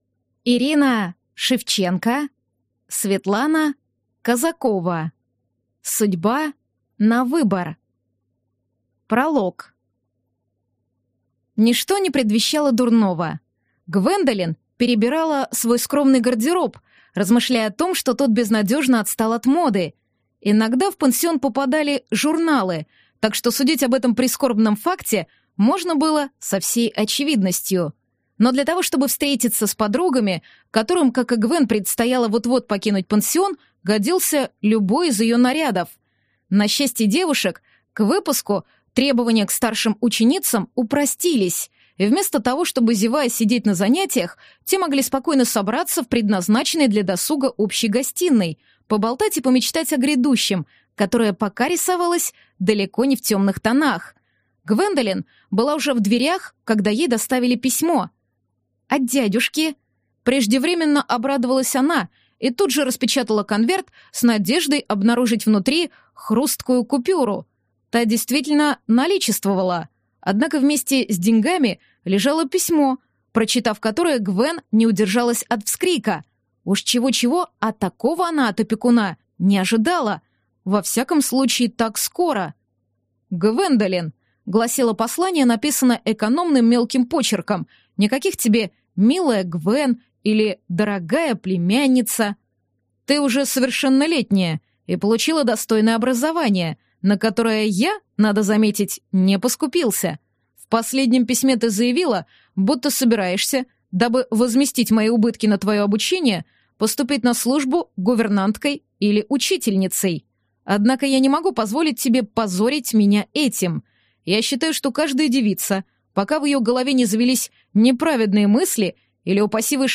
Аудиокнига Судьба на выбор | Библиотека аудиокниг